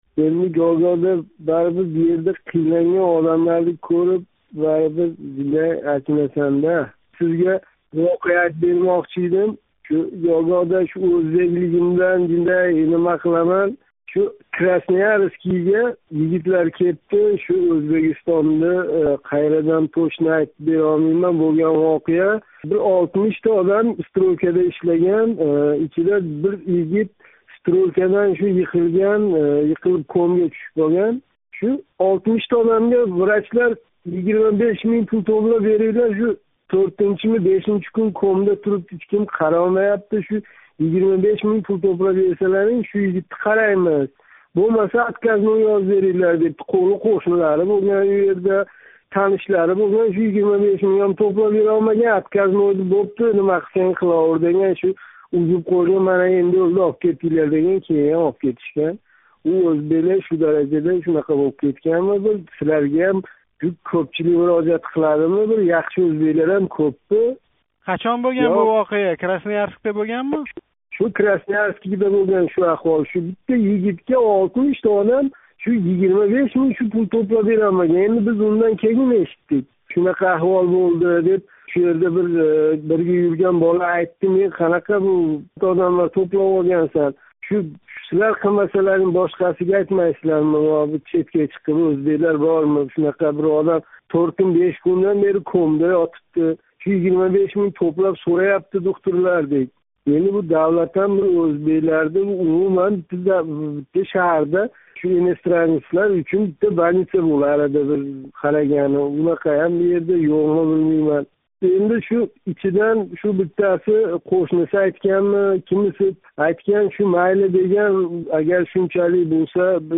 Озодликнинг "Эркин микрофон"ига қўнғироқ қилган ўзбекистонликлар хорижда ишлаётган ватандошларига ана шундай тавсия бермоқдалар.